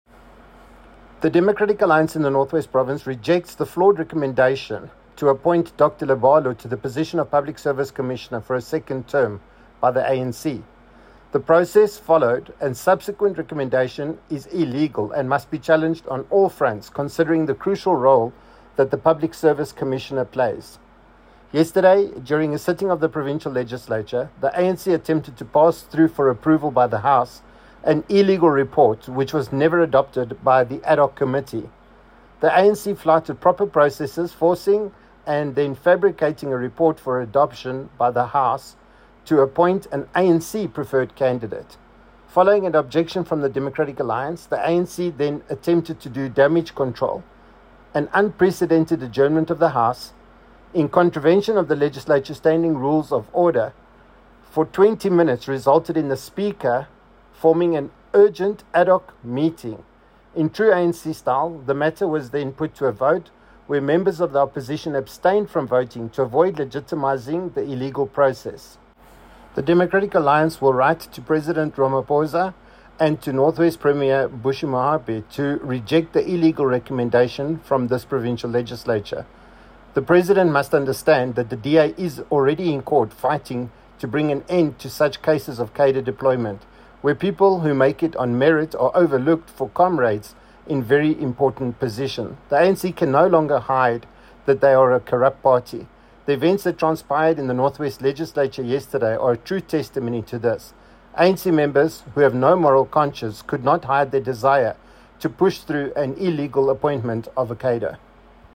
Note to Broadcasters: Find linked soundbite in
English  by Gavin Edwards MPL